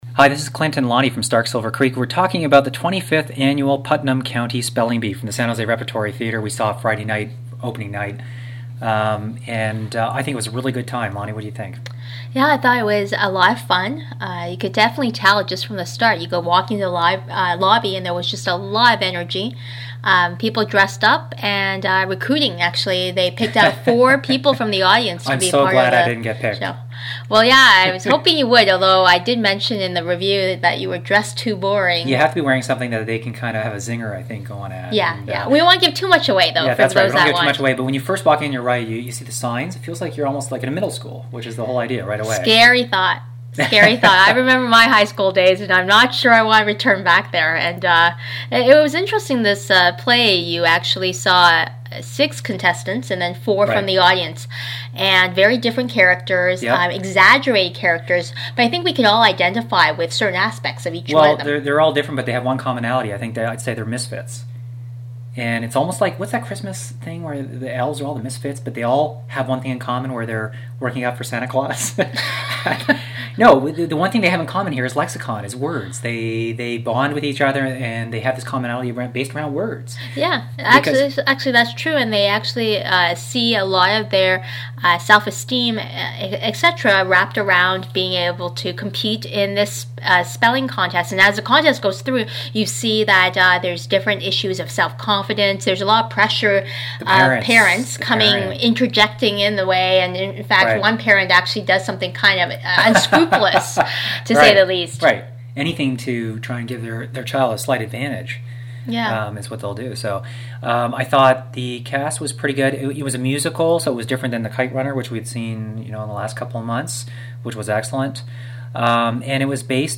discuss Spelling Bee: